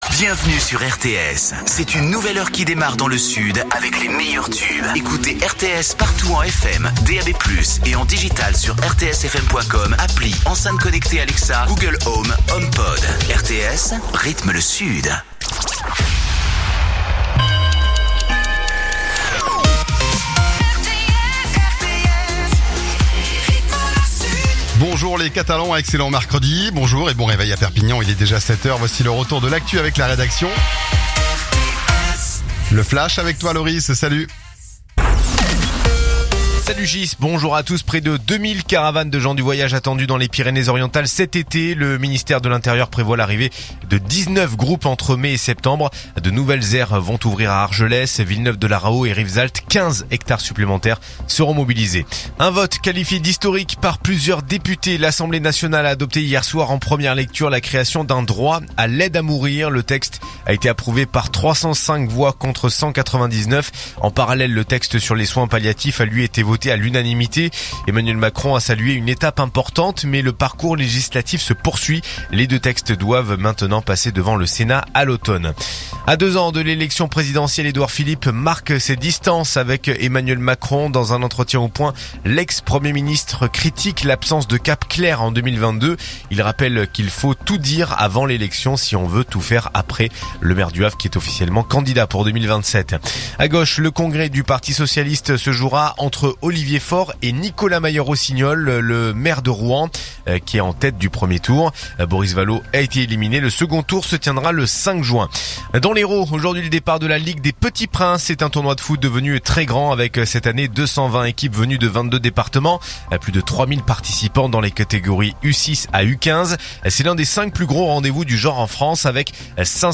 info_perpignan_397.mp3